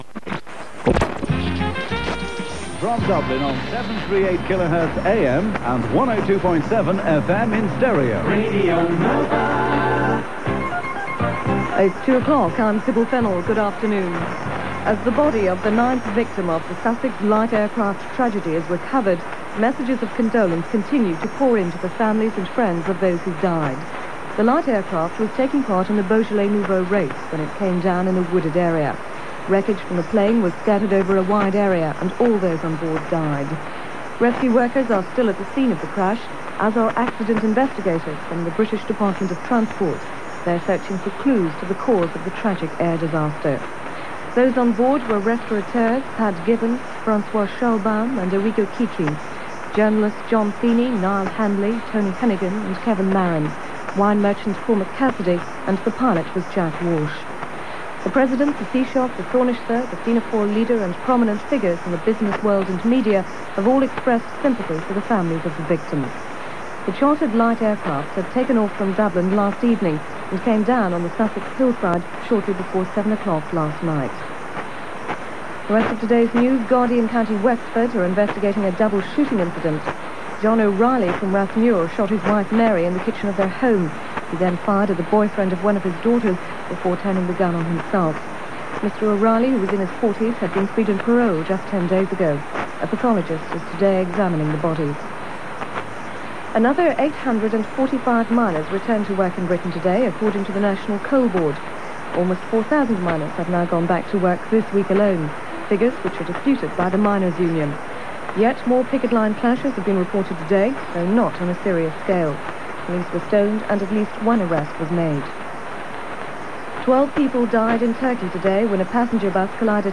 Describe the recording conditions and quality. The station broadcast on 1512 kHz AM and soon added FM, an innovation at the time. It lasted until 1982, by which time the larger and more professional stations ERI and South Coast Radio were making inroads in Cork city and county.